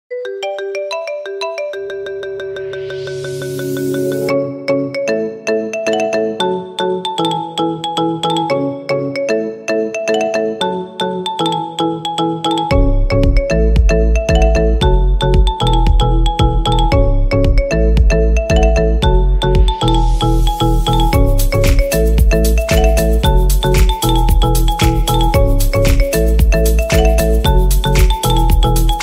Kategori Marimba